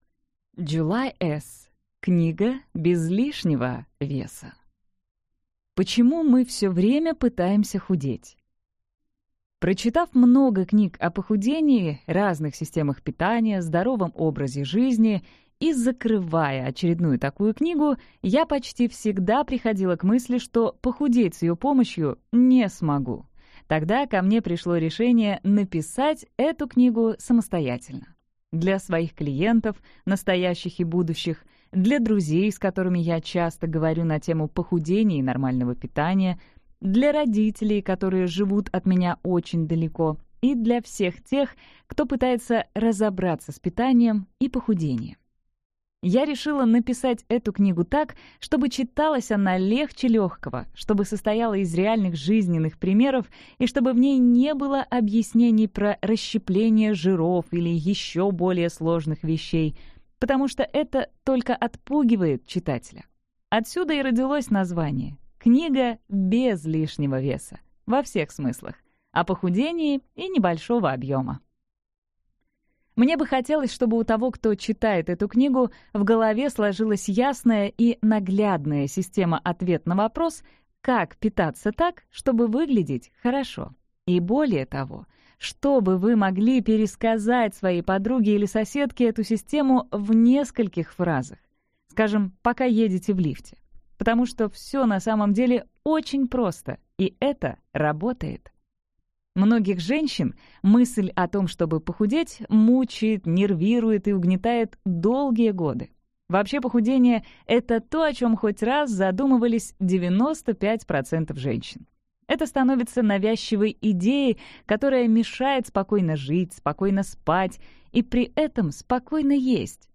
Аудиокнига Книга без лишнего веса | Библиотека аудиокниг